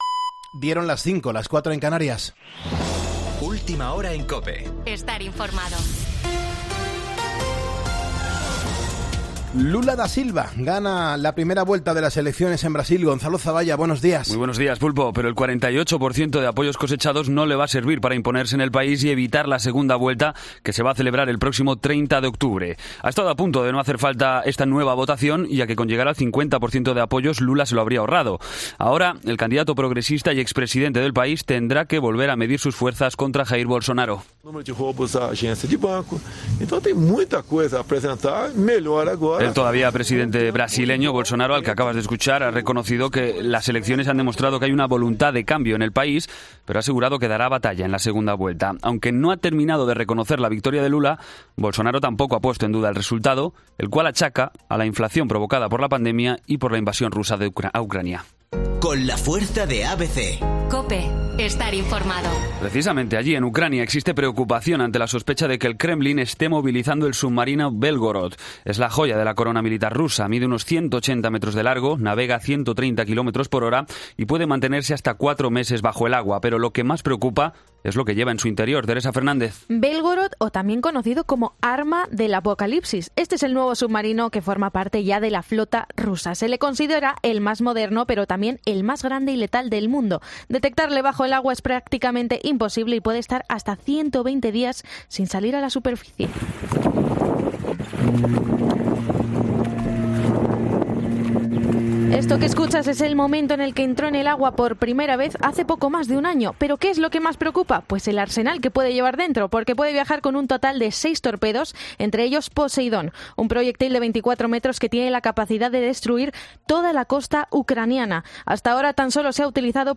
Boletín de noticias COPE del 03 de octubre a las 05:00 hora